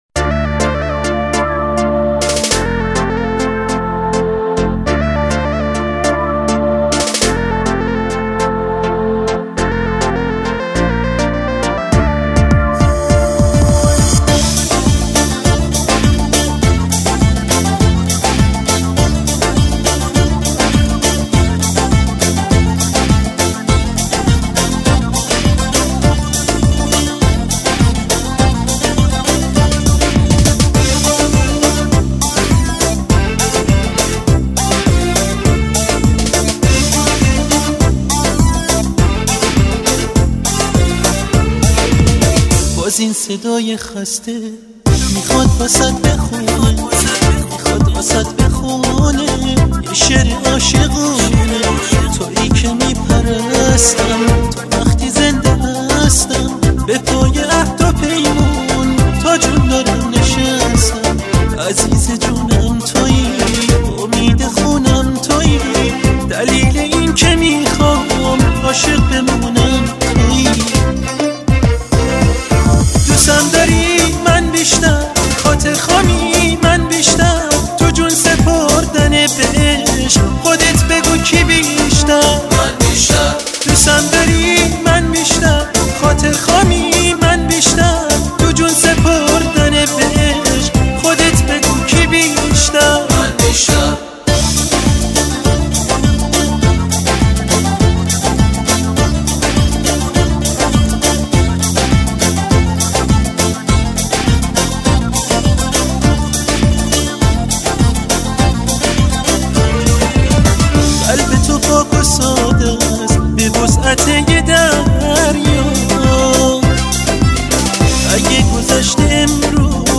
اهنگ شاد ایرانی آهنگ عروسی